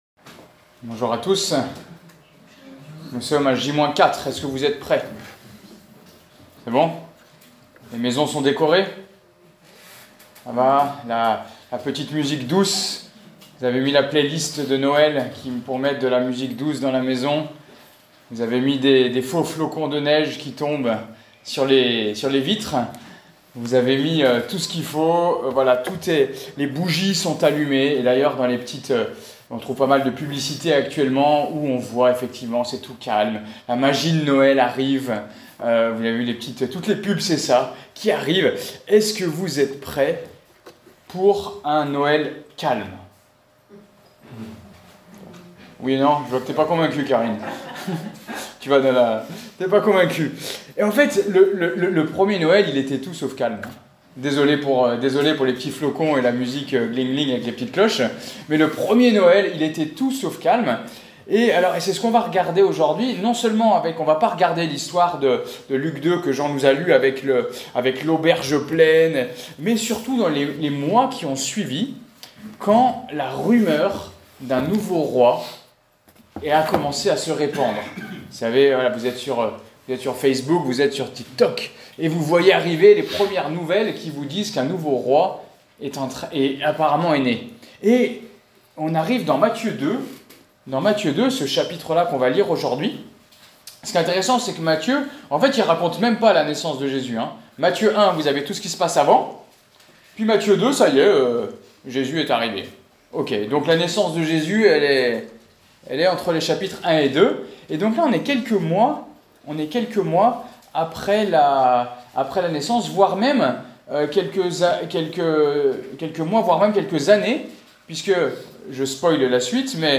Prédications bibliques